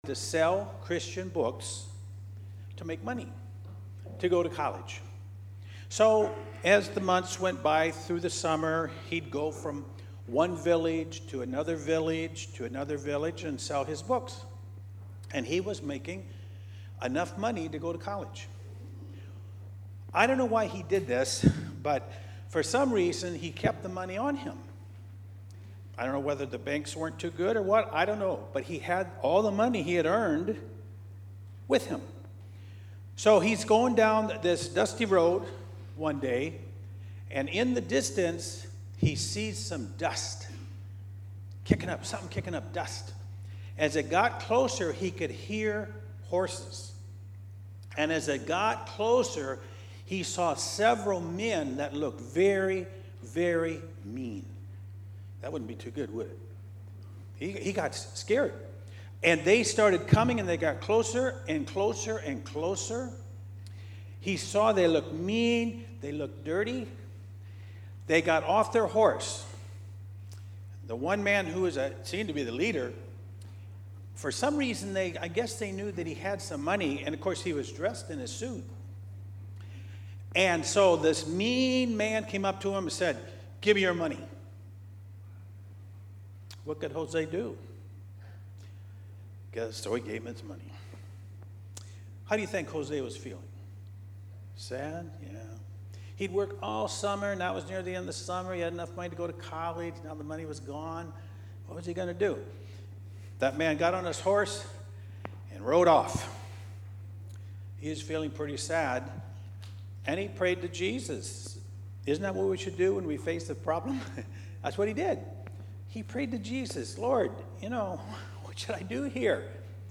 Past Sermons